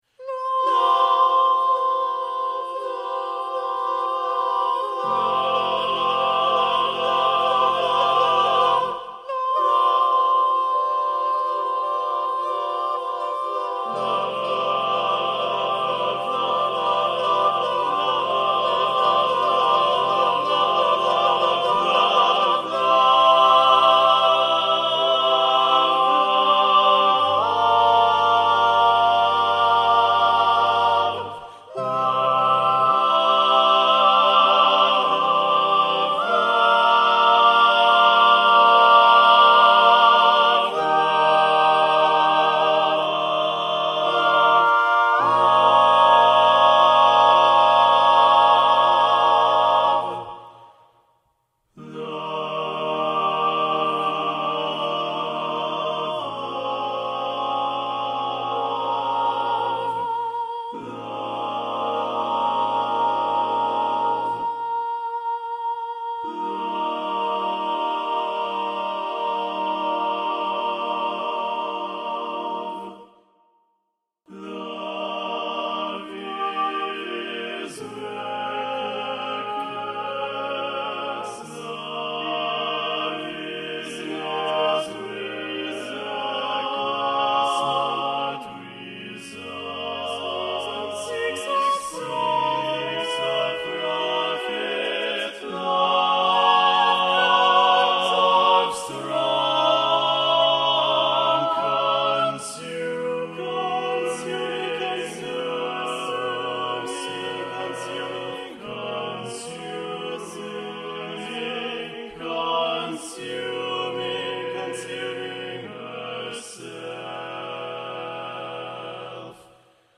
for SATB choir, divisi